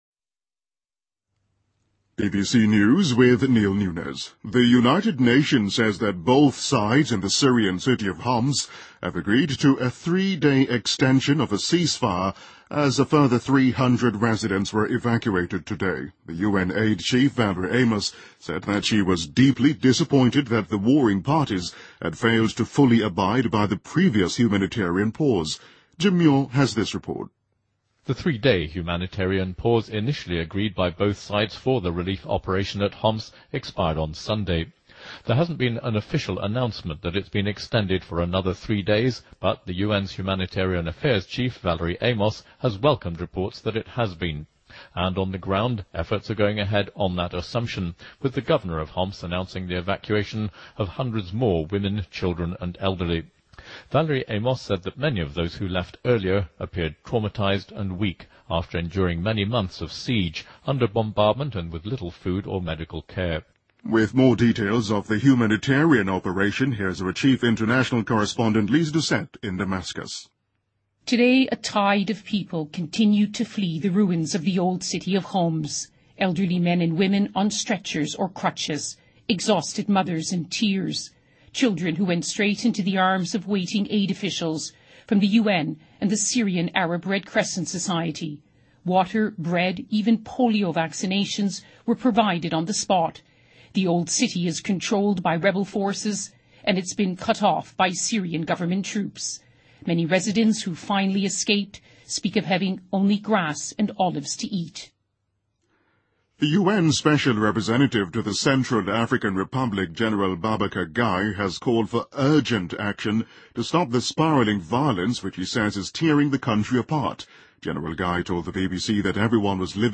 BBC news,2014-02-11